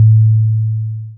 dong.wav